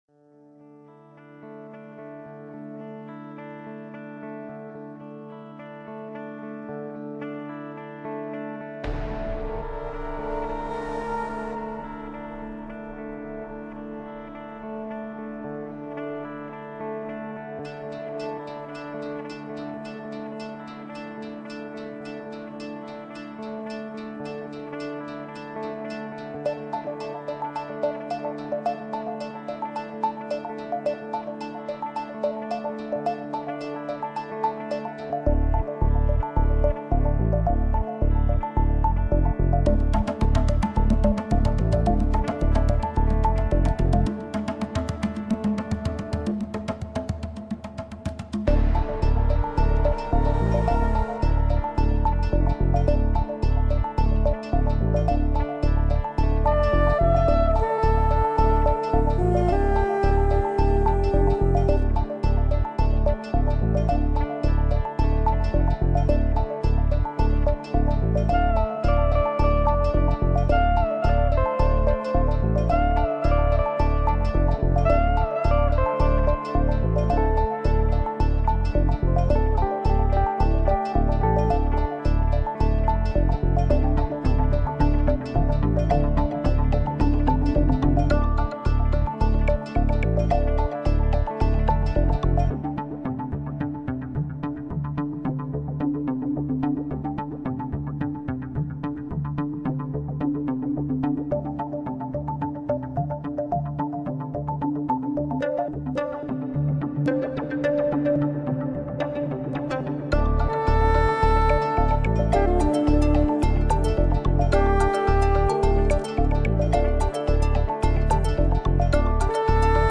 newage sounds to uplift